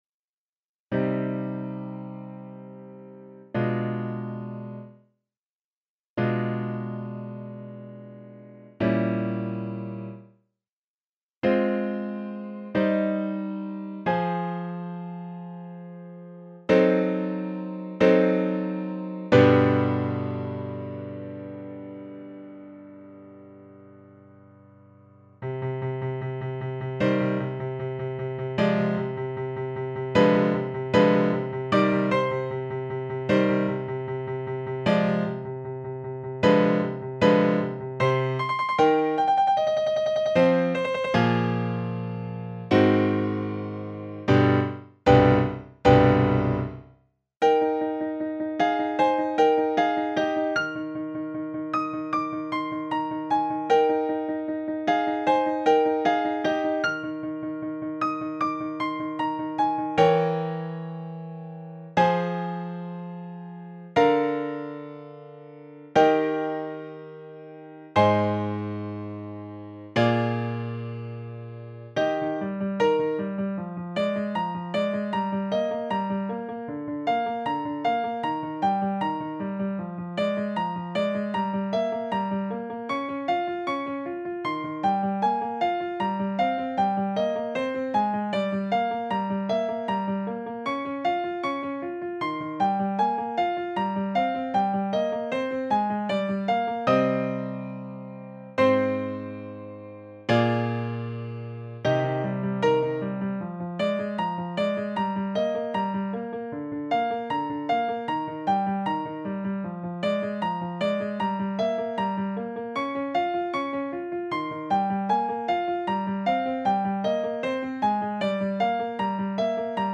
The music is created by electronic sounds and instruments, which are sequenced by musicians who become the author. The sounds, therefore, do not come from an orchestra, but the result is often pleasenty surprising.
Sonata Movement in C minor for piano folio 117 recto.